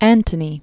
Mark An·to·ny
(ăntə-nē) or Mark An·tho·ny (ănthə-nē)Originally Marcus Antonius. 83?–30 BC.